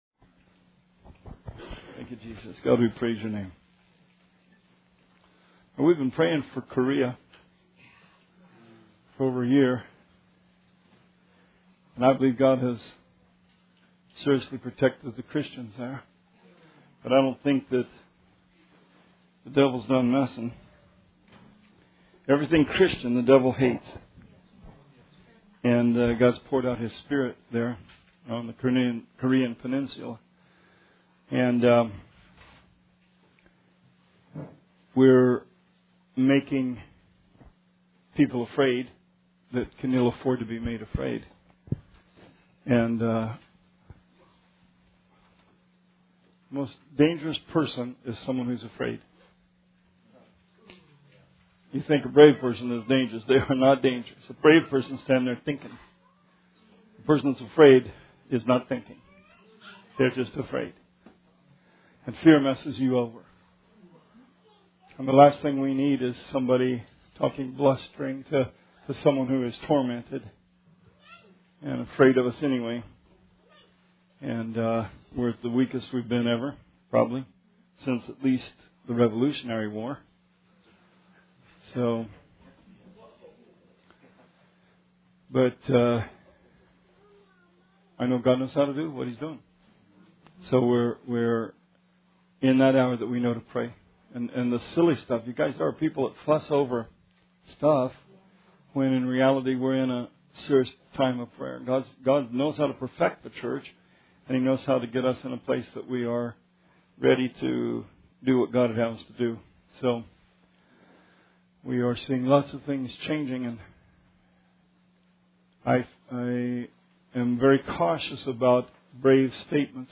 Bible Study 4/5/17